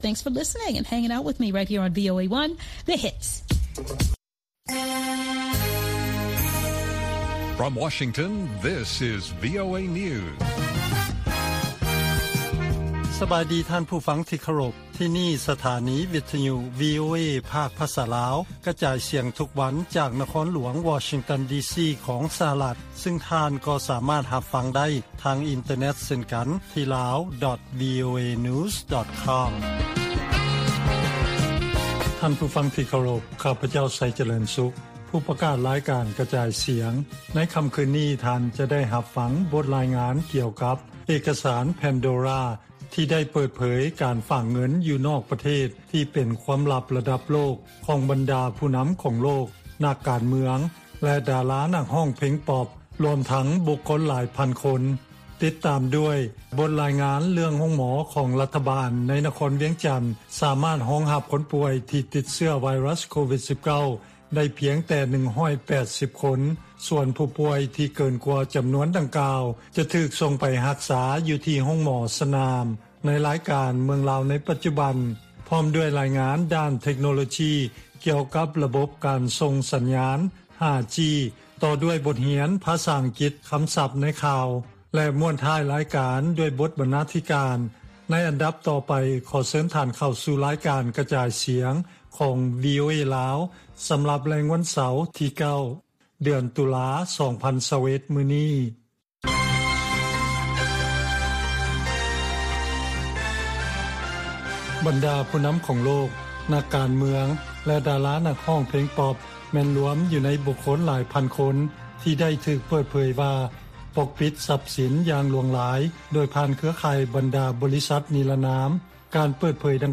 ວີໂອເອພາກພາສາລາວ ກະຈາຍສຽງທຸກໆວັນ. ຫົວຂໍ້ຂ່າວສໍາຄັນໃນມື້ນີ້ມີ: 1) ໂຮງໝໍລັດຖະບານລາວ ສາມາດຮັບຄົນປ່ວຍຈາກໂຄວິດ-19 ໄດ້ພຽງແຕ່ 180 ຄົນ.